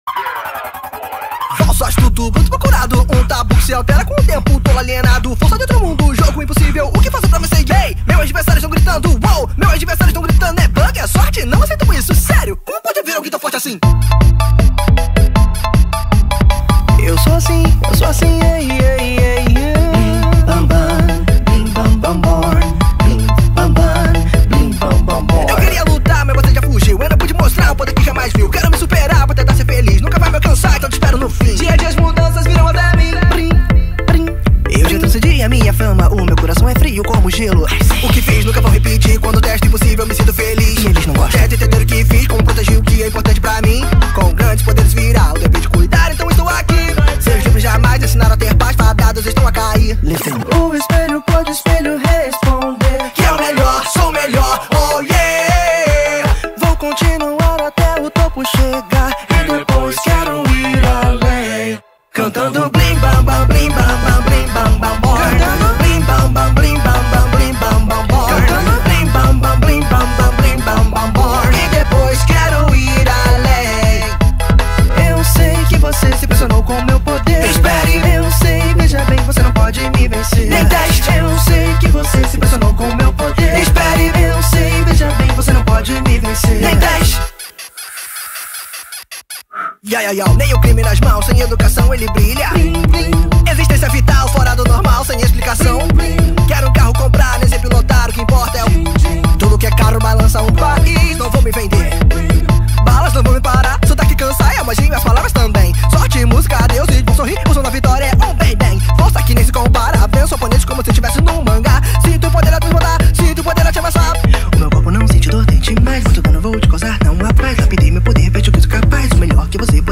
2025-02-23 16:01:10 Gênero: Rap Views